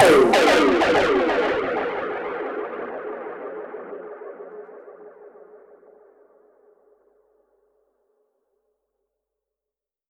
Index of /musicradar/dub-percussion-samples/95bpm
DPFX_PercHit_B_95-05.wav